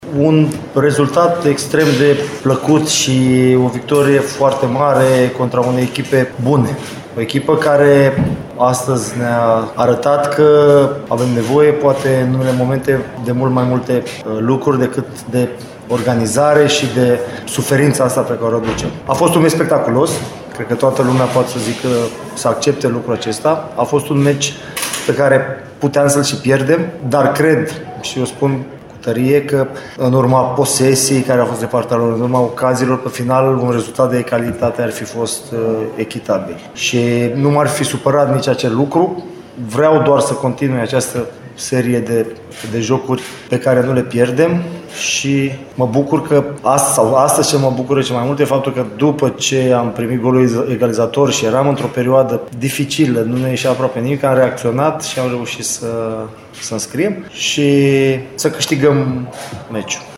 De cealaltă parte, antrenorul UTA-ei, Adrian Mihalcea, consideră că a fost un joc spectaculos, în care balanța se putea înclina în orice parte. Mai mult, el consideră că o remiză ar fi fost astăzi rezultatul corect: